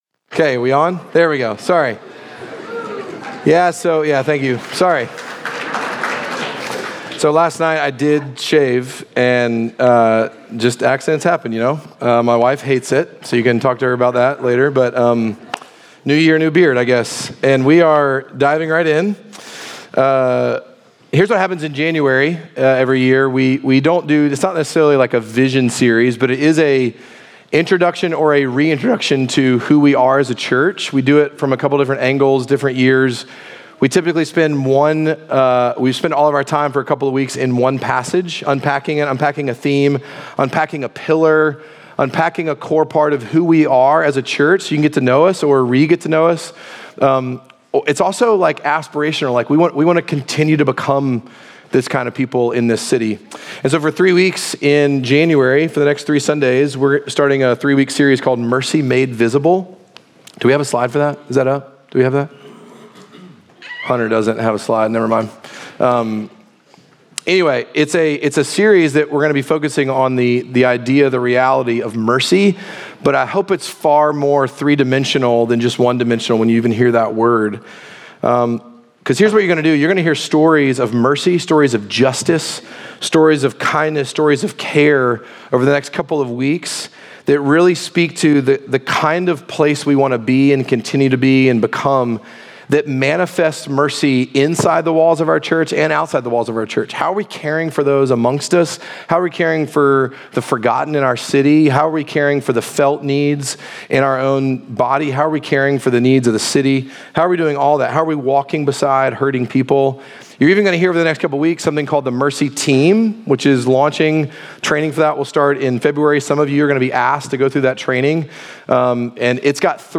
Midtown Fellowship 12 South Sermons Justice Jan 04 2026 | 00:51:16 1x 00:00 / 00:51:16 Subscribe Share Apple Podcasts Spotify Overcast RSS Feed Share Link Embed